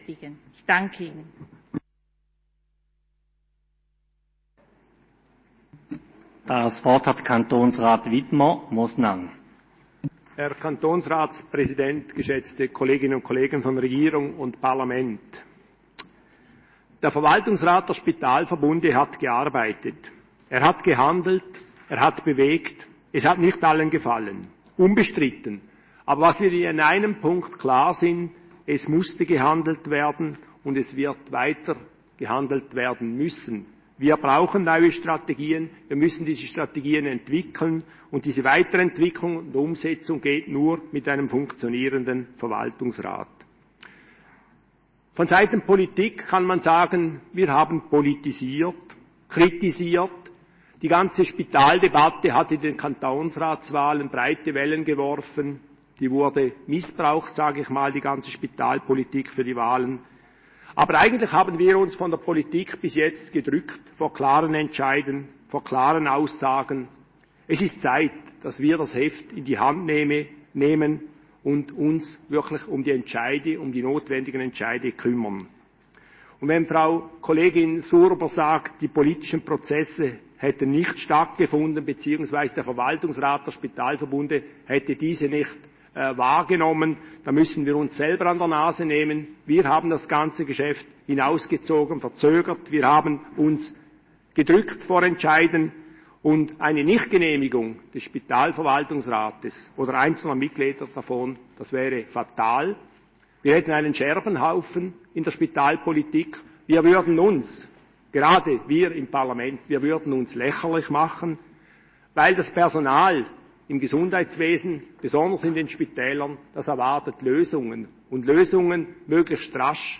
Session des Kantonsrates vom 2. bis 4. Juni 2020
(im Namen der CVP-EVP-Fraktion):